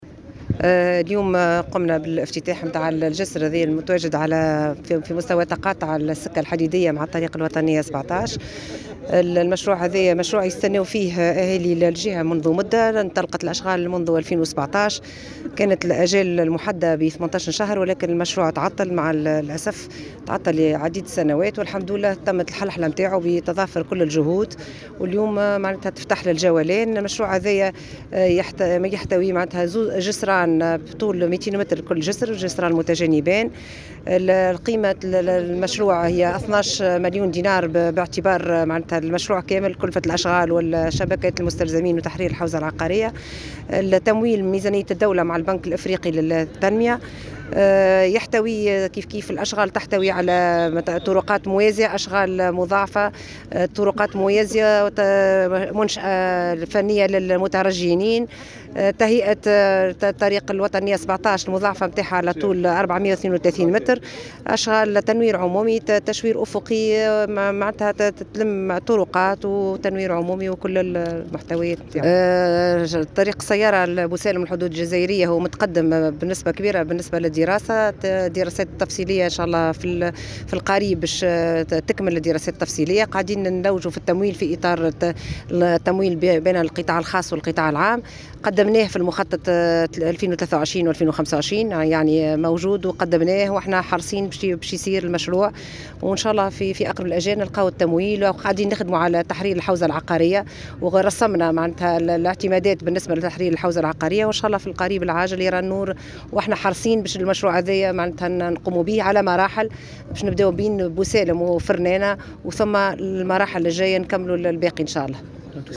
وقالت وزيرة التجهيز في تصريح لمراسل الجوهرة اف ام، إن المشروع يحتوي على جسرين متجانبيْن بطول 200 متر لكل جسر، بالإضافة إلى طرقات موازية ومنشأة فنية للمترجلين وتنوير عمومي.